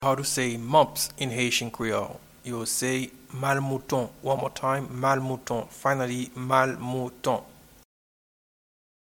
Pronunciation and Transcript:
Mumps-in-Haitian-Creole-–-Malmouton.mp3